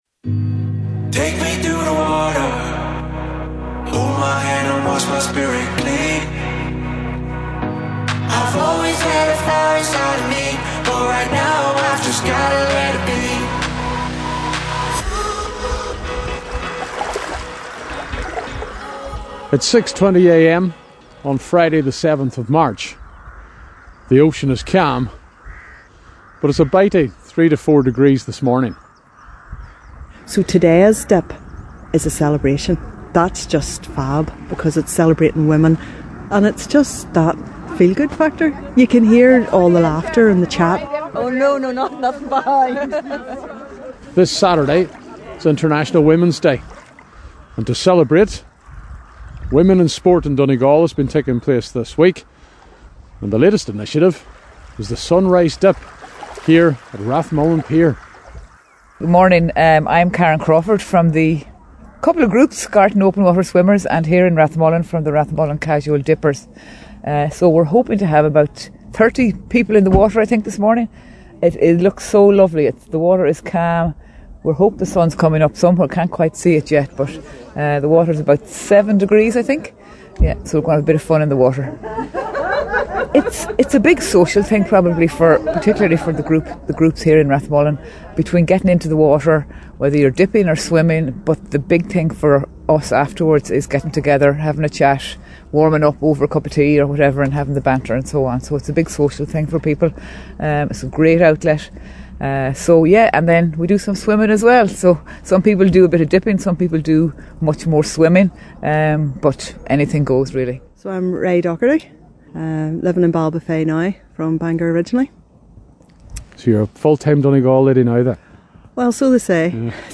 As part of Women in Sport Week, hosted by the Donegal Sports Partnership and in the lead up to International Women’s Day, the Sunrise Dip took place on Friday morning at Rathmullan Pier.